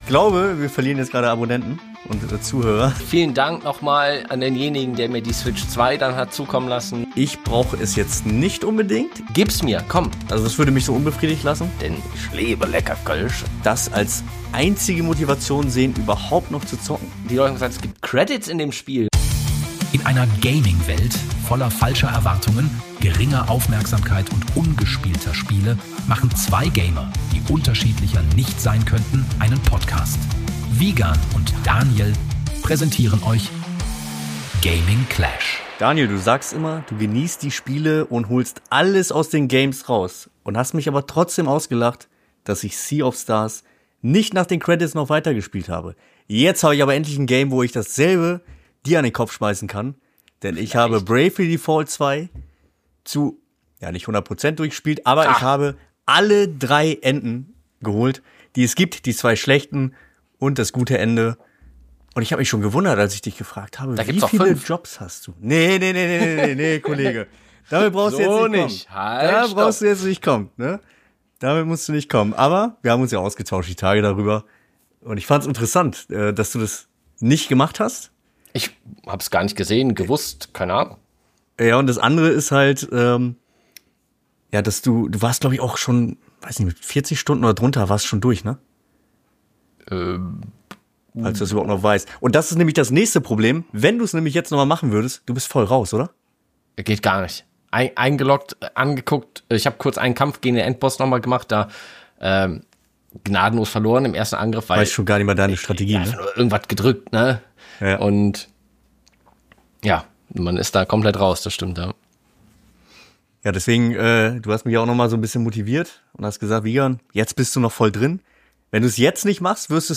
Neben neben den Gaming Erfolgen kannst du dich auf viel Humor, viele Lacher, Metroid, die Nintendo Switch 2 und den altbekannten Media Markt Trick freuen.